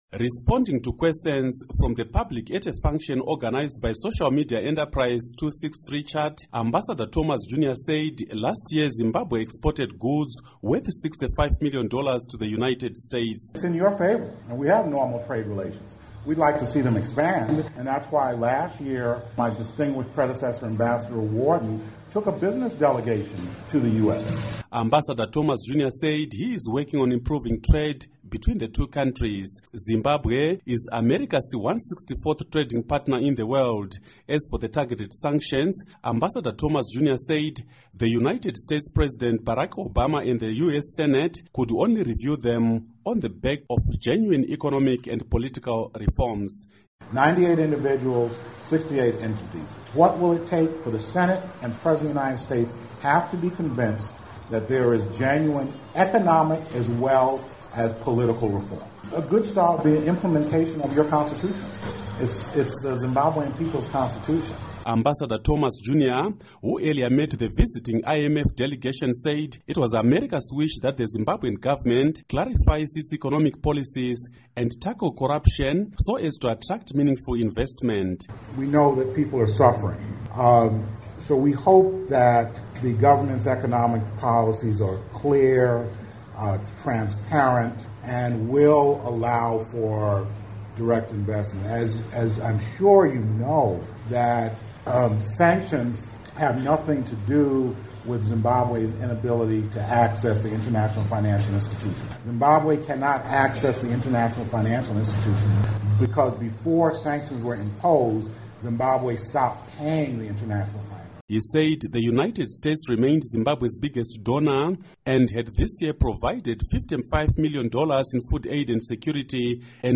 Report on Zimbabwe, US Relations